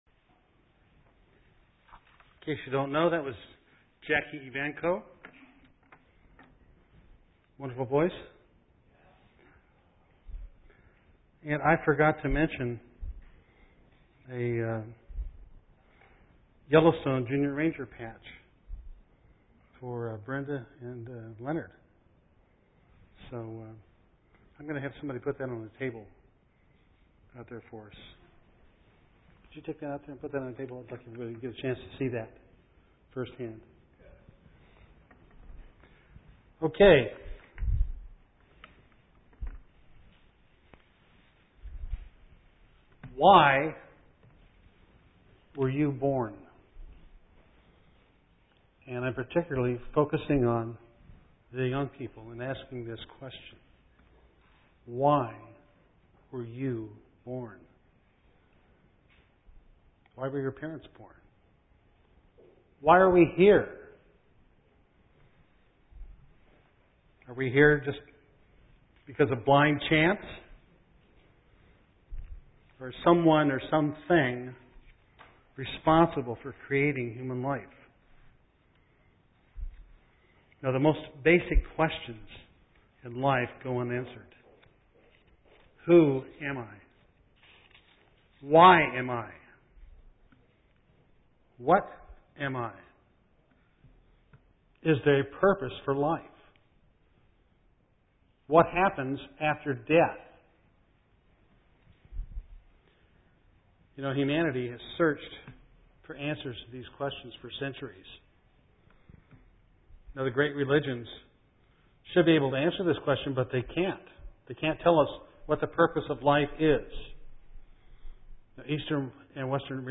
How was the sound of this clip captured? Given in Eureka, CA